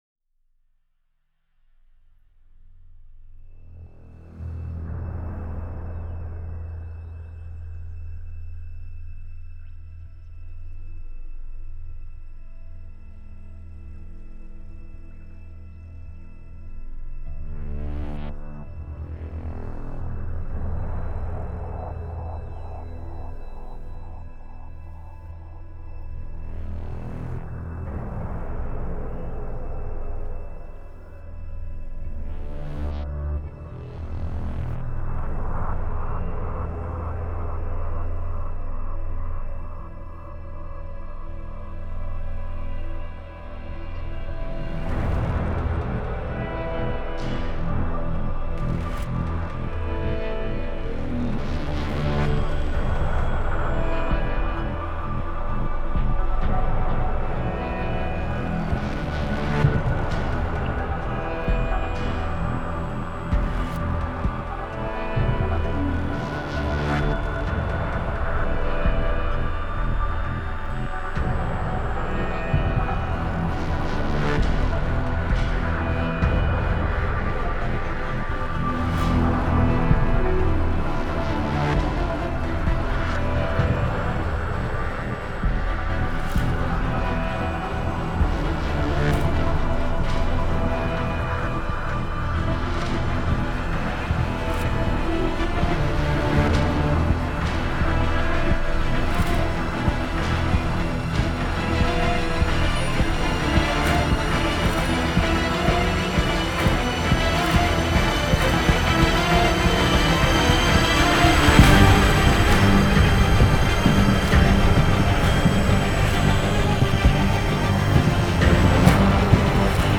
BEST ORIGINAL SCORE